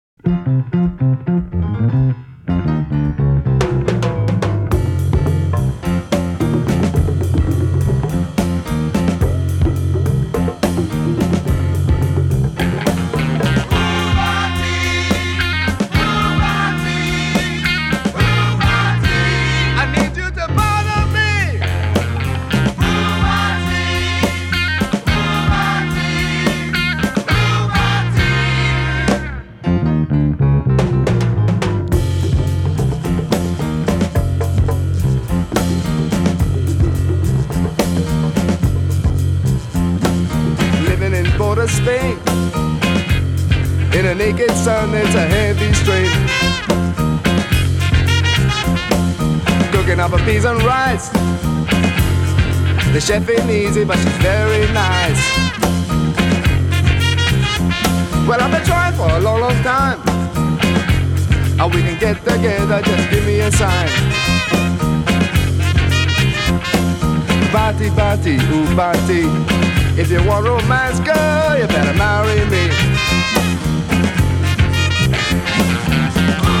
A fusion of jazz, rock and Afro influences